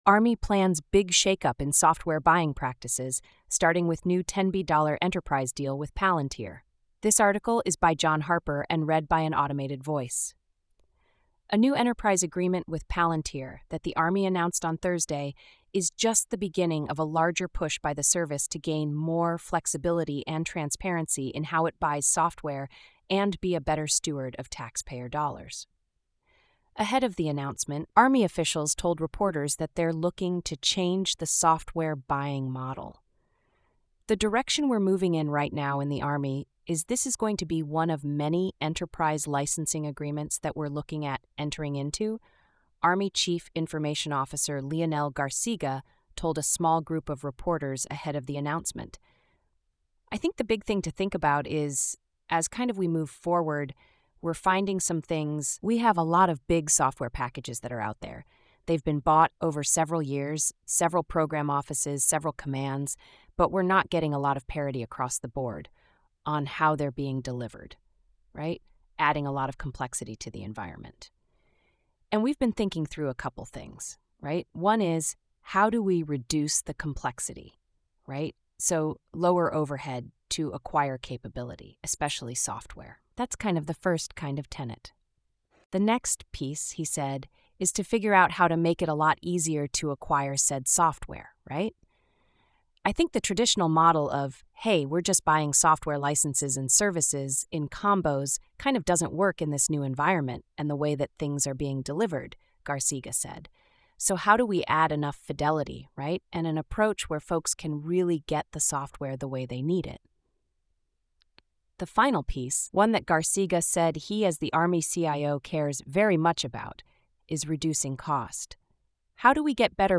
This feature uses an automated voice, which may result in occasional errors in pronunciation, tone, or sentiment.